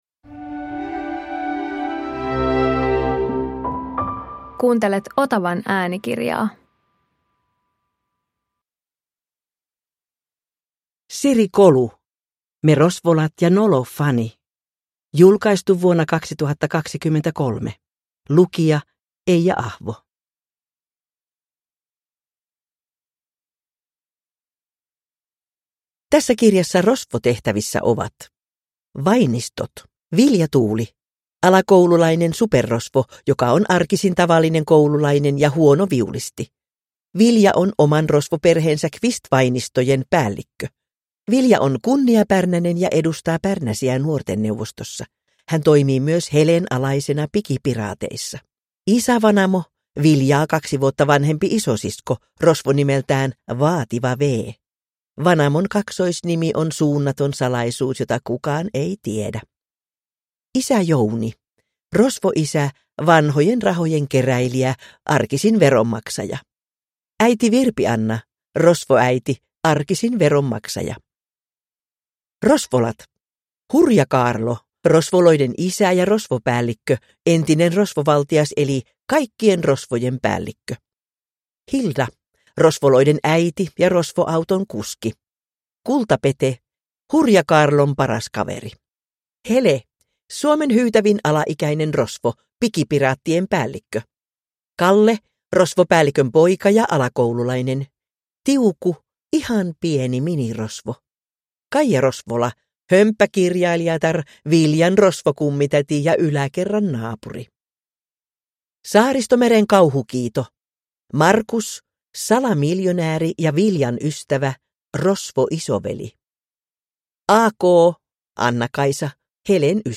Me Rosvolat ja nolo fani – Ljudbok